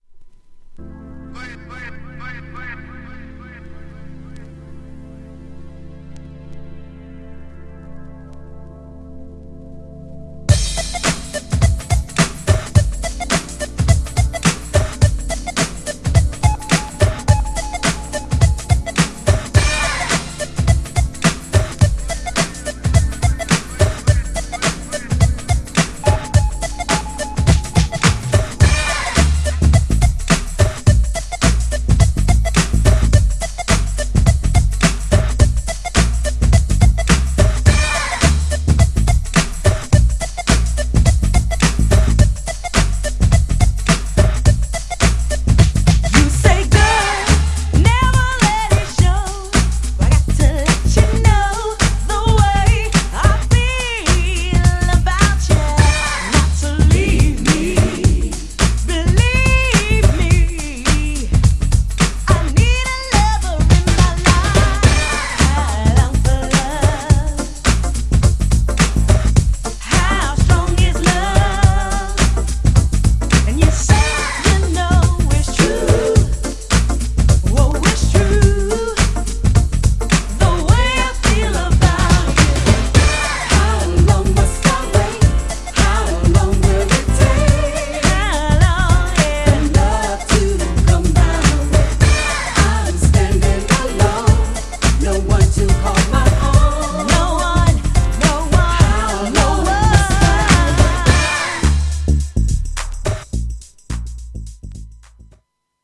GENRE House
BPM 121〜125BPM